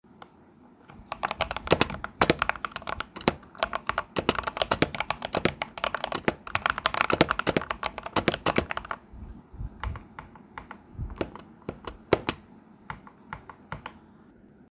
Switch Type Corsair MLX RED (linear)
I’ve come to like the feel of it and just how buttery smooth each key feels, and the foam did a good job at keeping the noise low and reasonable even at rapid typing sessions.
For that, here’s a quick test on how it sounds like: